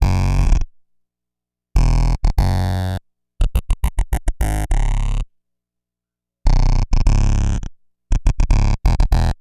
Bass 35.wav